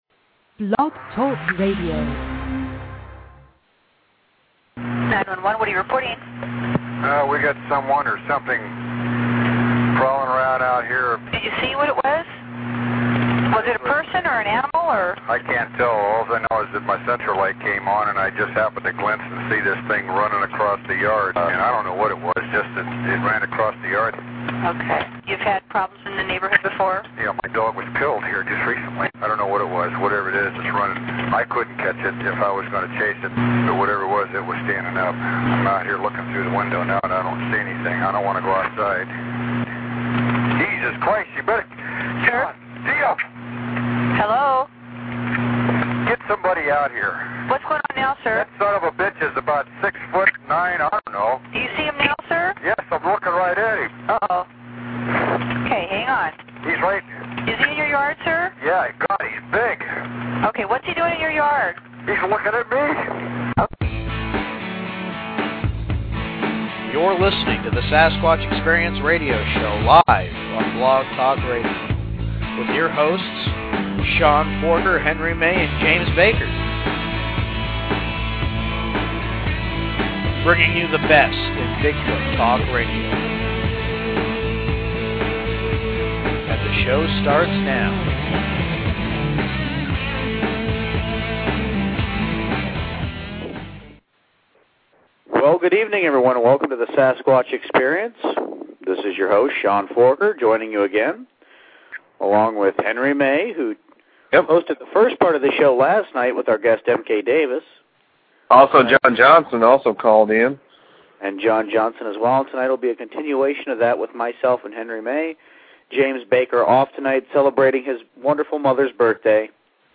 File usage The following page links to this file: (Radio Show) The Sasquatch Experience